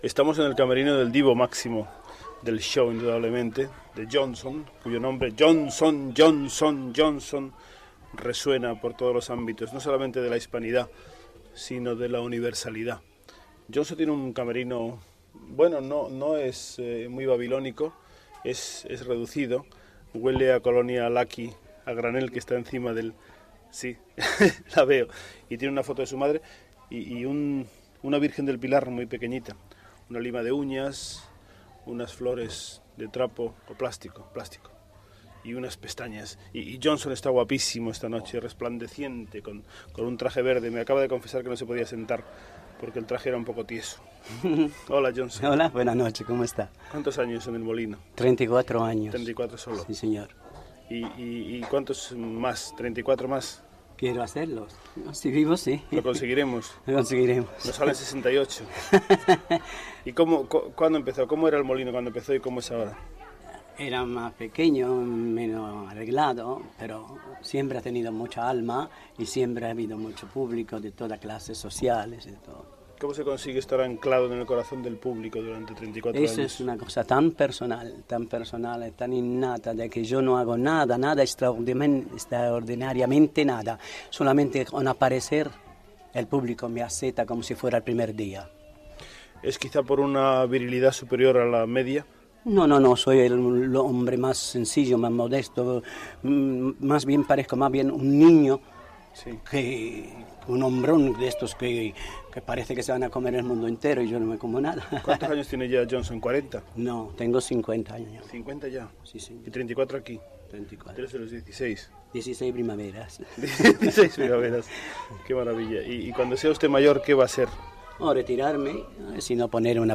Entrevista
feta al seu camerí del teatre El Molino del Paral·lel de Barcelona